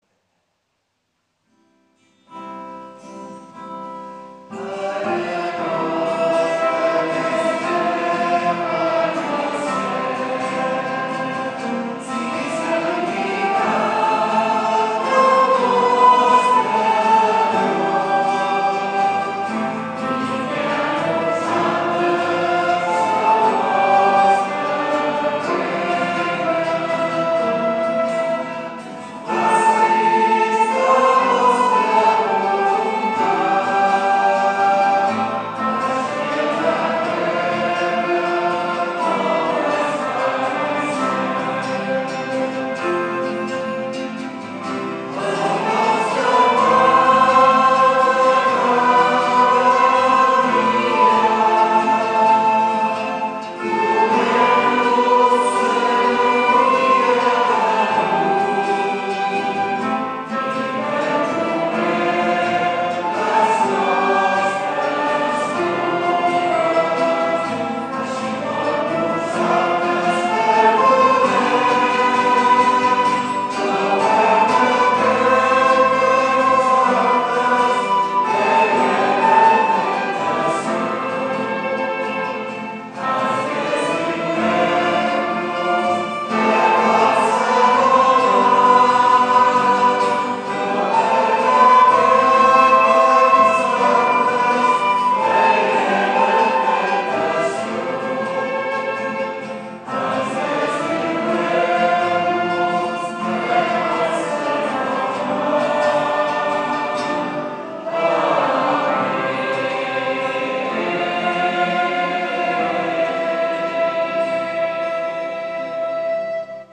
Pregària de Taizé
Parròquia de la M.D. de Montserrat - Diumenge 23 de febrer de 2014